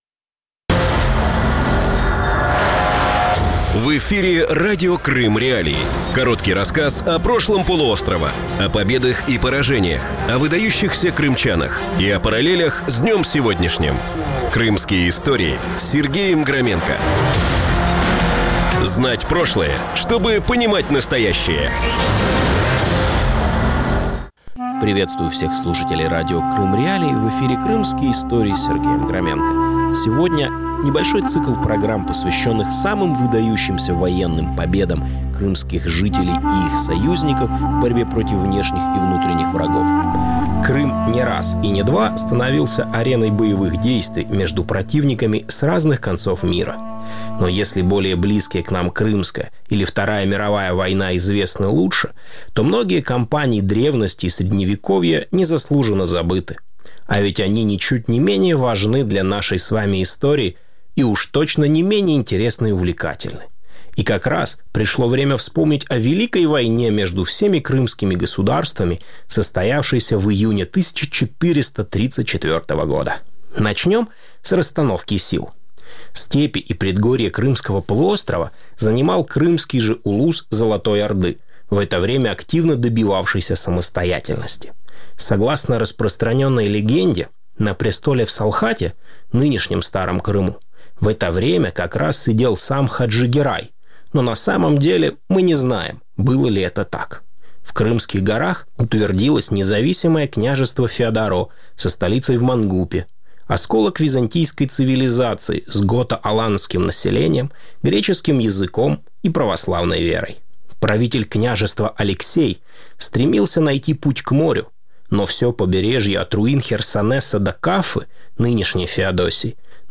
Первый посленовогодний выпуск Крымских.Историй – это сборник лучших коротких рассказов о прошлом полуострова, объединенных общей темой. Слушатель вспомнит о наиболее значимых событиях крымской милитарной истории: генуэзской войне 1434 года, битвах под Желтыми Водами и Конотопе, восстании в Севастополе в 1830 году и, наконец, о судьбе Крыма в Первую мировую войну.